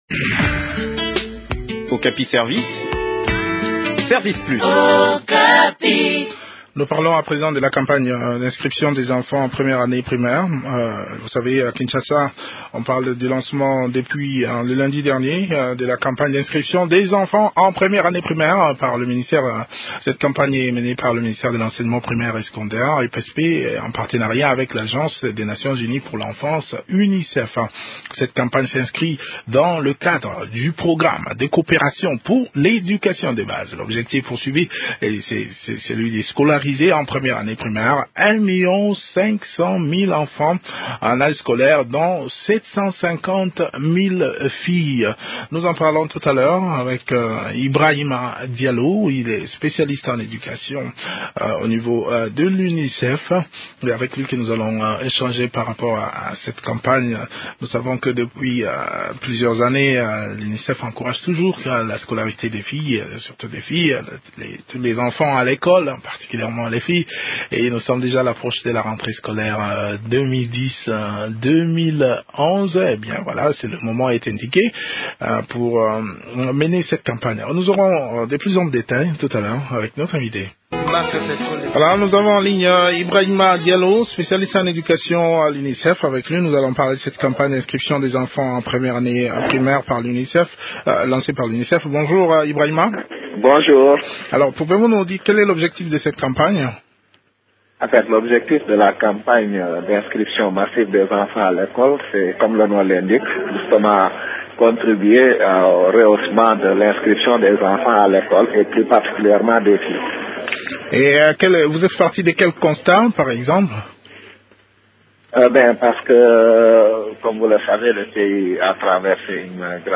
Le point sur le déroulement de cette campagne dans cet entretien